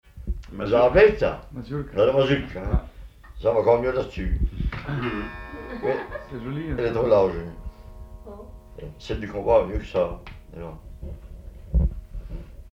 Localisation Magland
Catégorie Témoignage